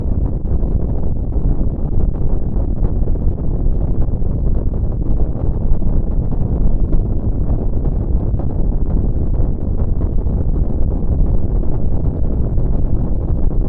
BlackHoleHowl.wav